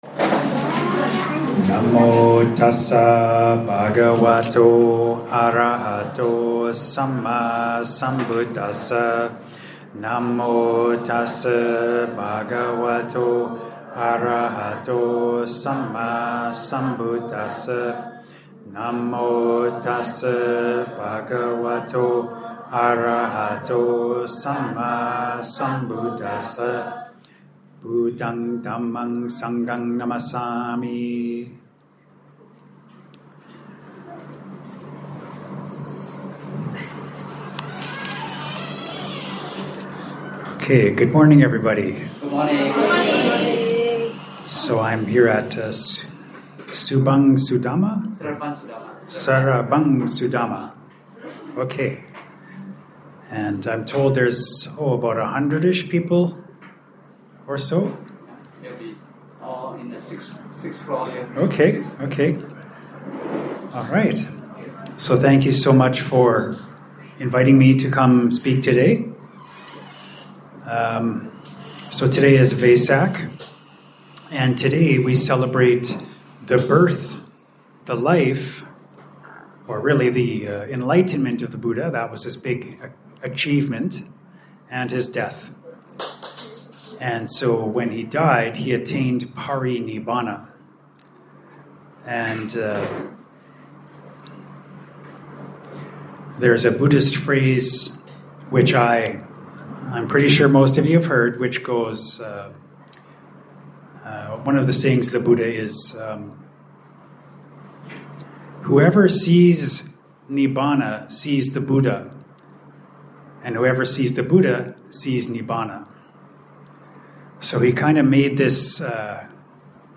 Recorded at Seremban Sudhamma Buddhist Association, Malaysia, given to an audience of about 100, here’s a 30-min recorded audio-only Dhamma Talk - downloadable 21MB .mp3 (Tip: tap and hold to “Download link”, or right click to “Save Link As…“). This was on the occasion of their Vesak; May 12, 2025.